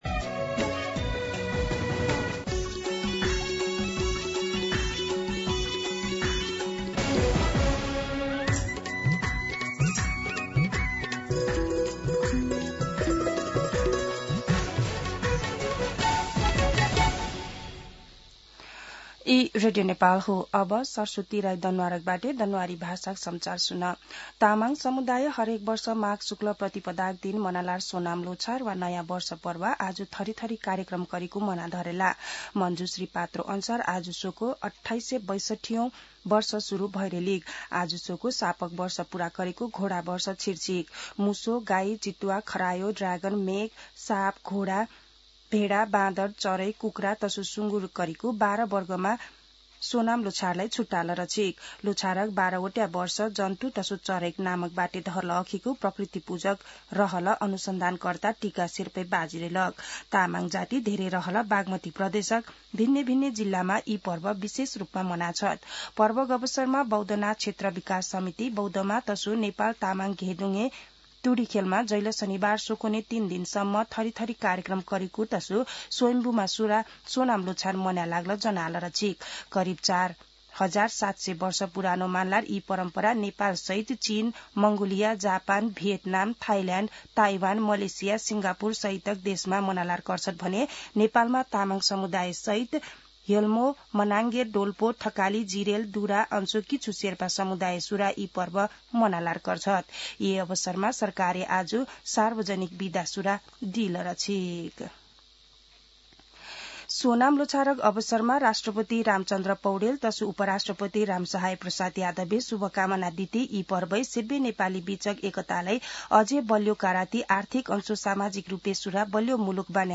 दनुवार भाषामा समाचार : ५ माघ , २०८२
Danuwar-News-1-1.mp3